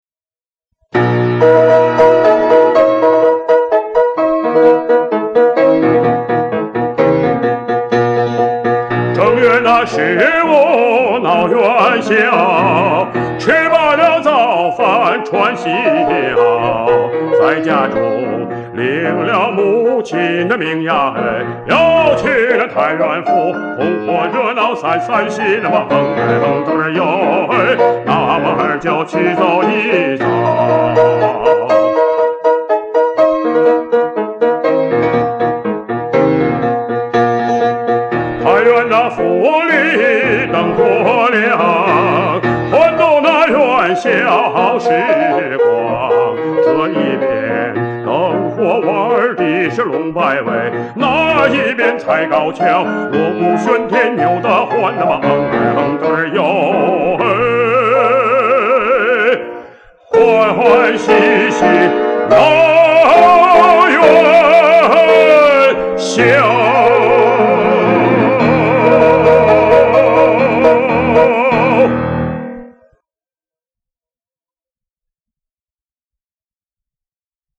[11/2/2017]著名歌唱家王凯平演唱的山西民歌《闹元霄》 激动社区，陪你一起慢慢变老！